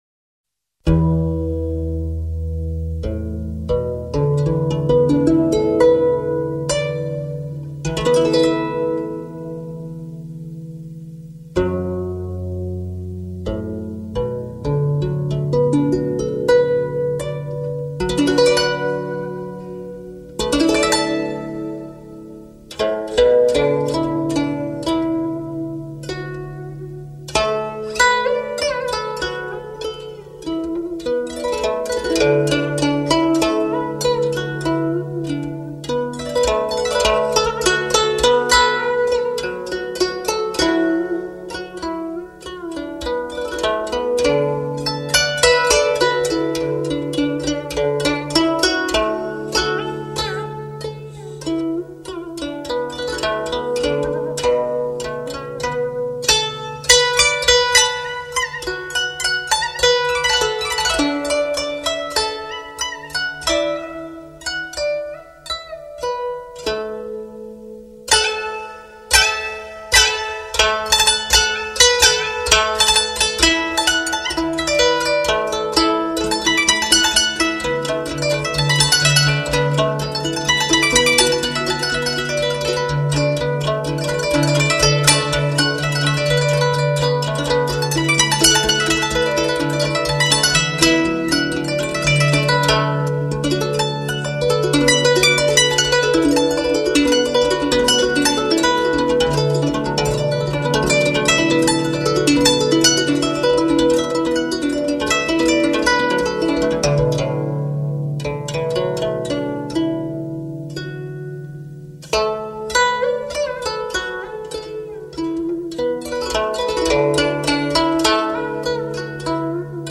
[29/8/2010]古筝独奏【浏阳河】